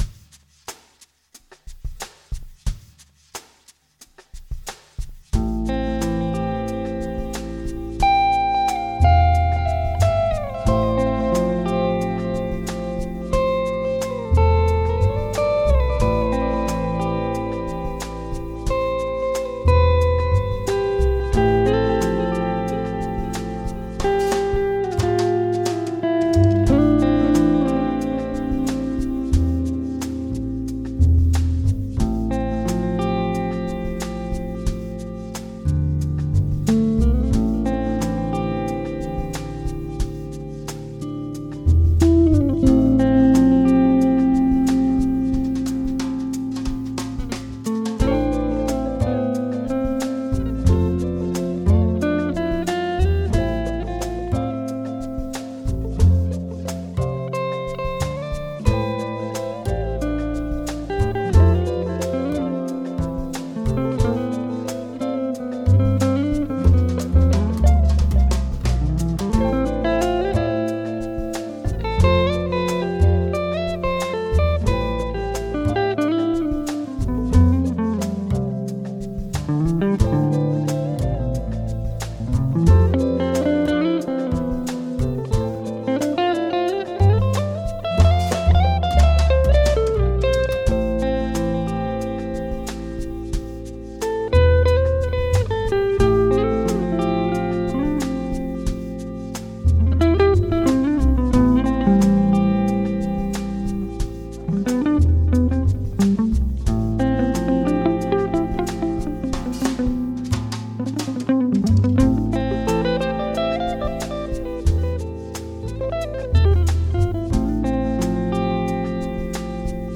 Compositeur et guitariste finlandais
des couleurs musicales jazzy et groovy
10 morceaux mélodiques